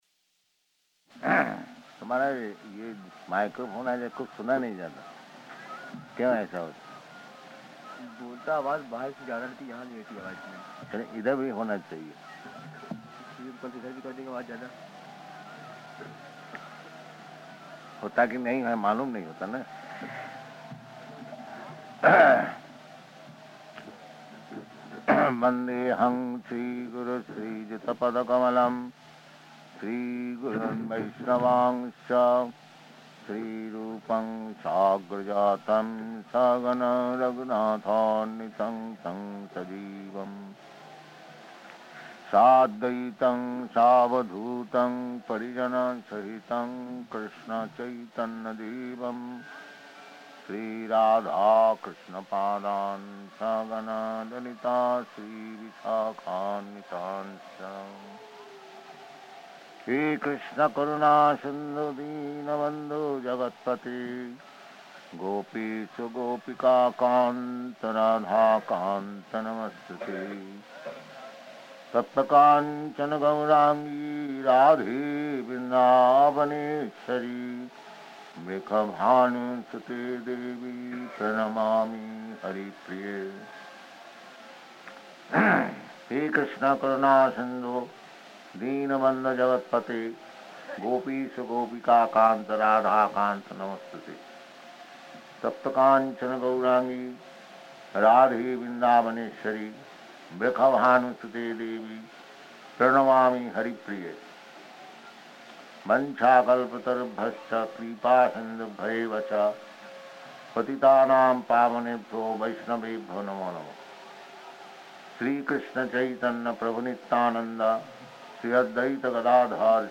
Location: Allahabad
[sings maṅgalacaraṇa prayers]